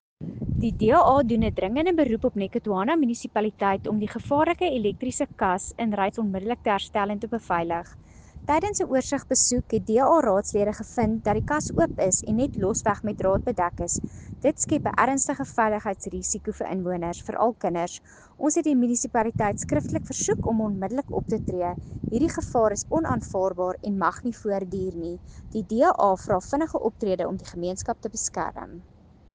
Afrikaans soundbites by Cllr Anelia Smit and Sesotho soundbite by David Masoeu MPL.